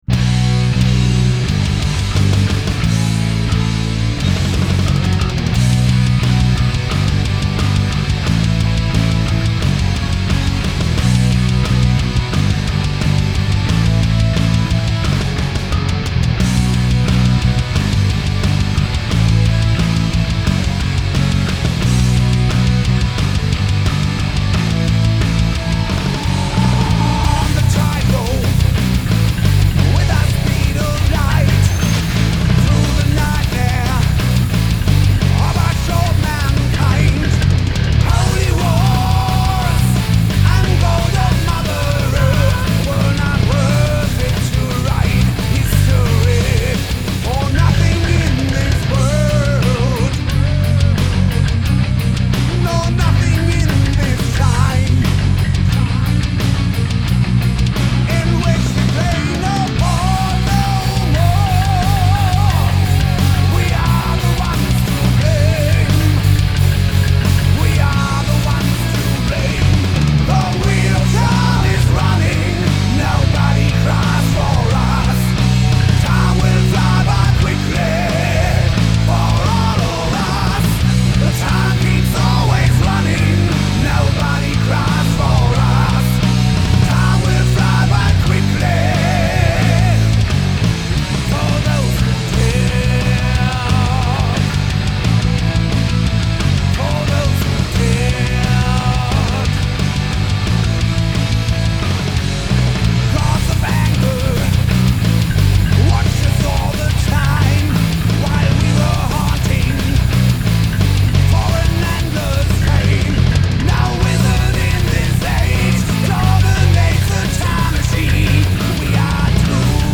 Und ich muss auch sagen, der kann echt Power Metal!